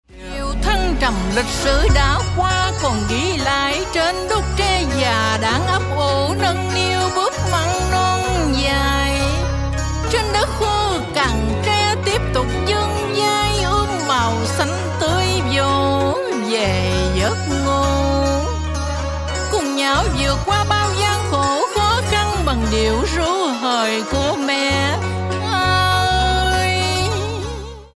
Nhạc Chuông Trữ Tình